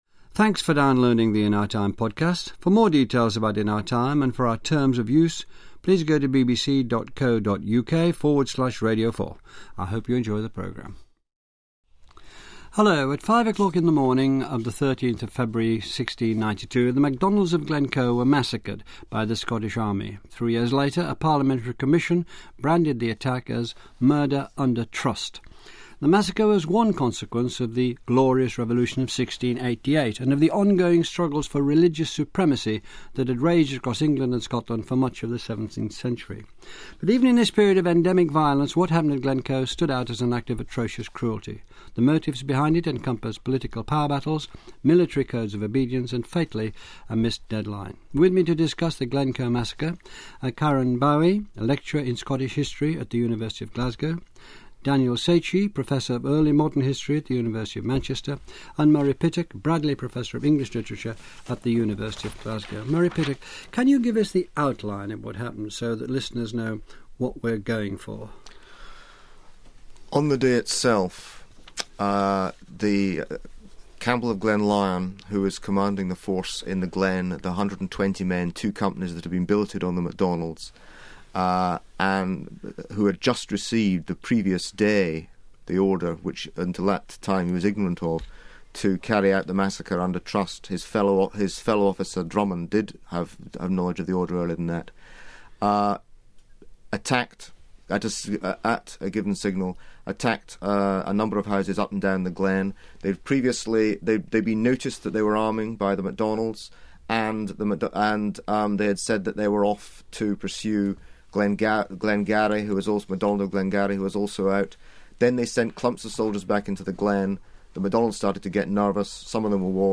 A discussion regarding the events leading up to the infamous "Massacre of Glencoe" which took place early in the morning of 13 February 1692, during the era of the "Glorious Revolution" and Jacobitism. From the BBC program "In our Time."